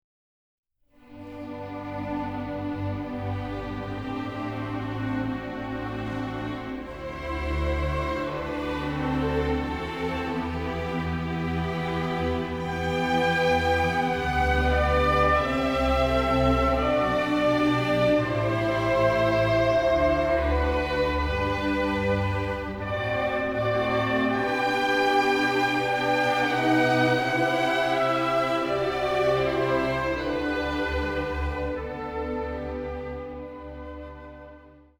gemischter Chor, Orgel, Instrumentalsolisten
• die Lieder werden mit besonderer Emotionalität vorgetragen